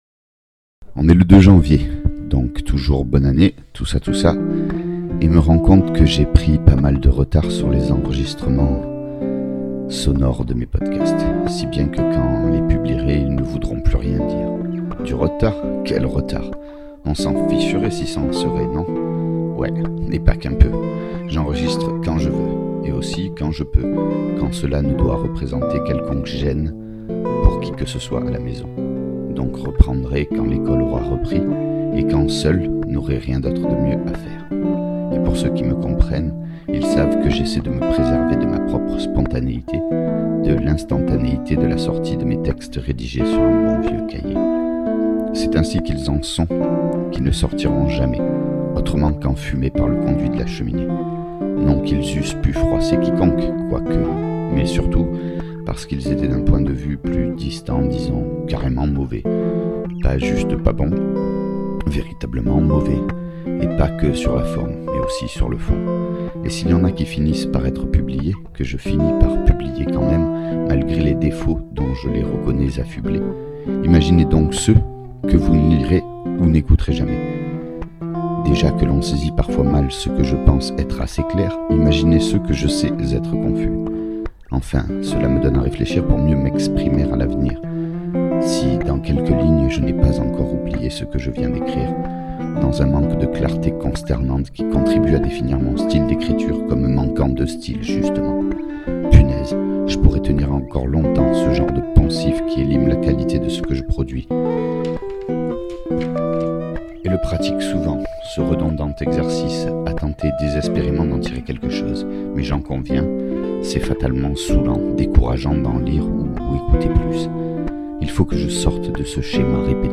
Le principe est tout simple : Un texte, comme une chronique, rédigé(e) avec un stylo sur un cahier (parce que c’est comme ça que j’écris), lu(e) devant un micro et accompagné(e) d’une improvisation à la guitare.
De plus je ne dispose pas d’un excellent matériel (un vieil ordi, une carte son externe M-Audio, un micro chant et une guitare) et je pense pouvoir améliorer la qualité du son, avec le temps et un peu d’investissement, mais prenez plutôt cet exercice comme une expérimentation de ma part, vous serez moins déçu(e)s. A noter : Les dates correspondent aux phases d’écriture, pas à l’enregistrement de la musique, mise en boîte quand il m’en vient la motivation.